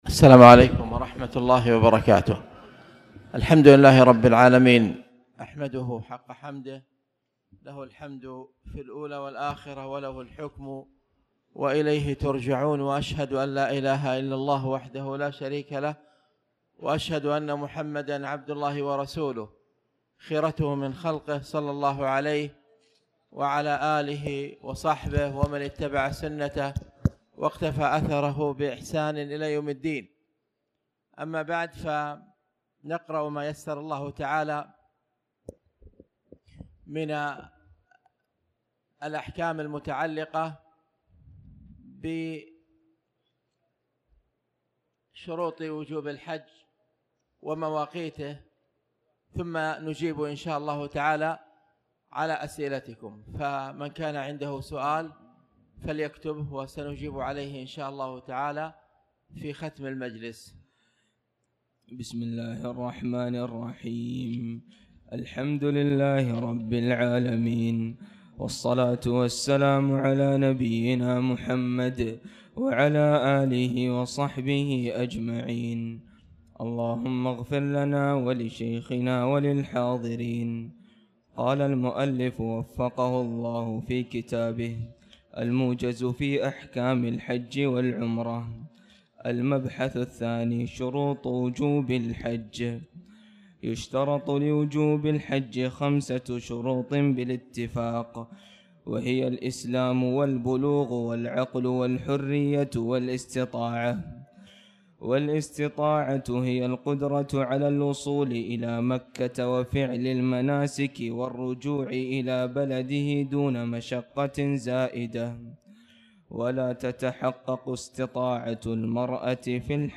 تاريخ النشر ١١ ذو القعدة ١٤٣٩ هـ المكان: المسجد الحرام الشيخ